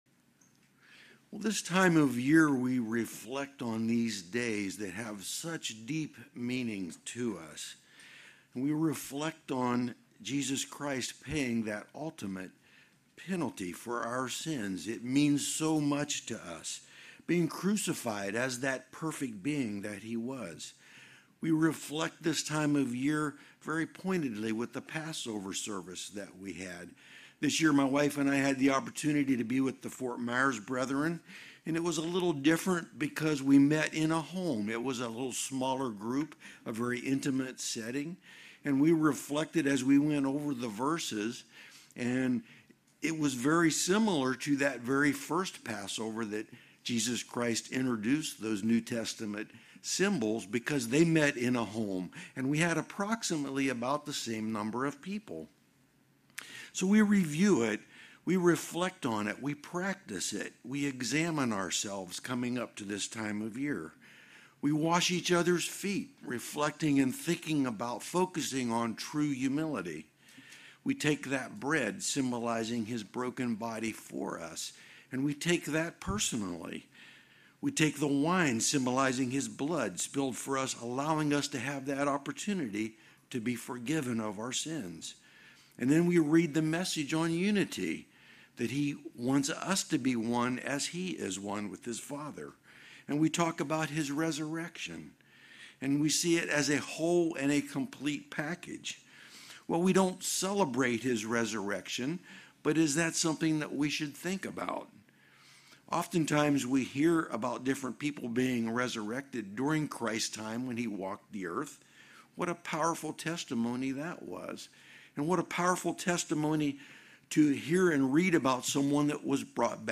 Sermons
Given in Tampa, FL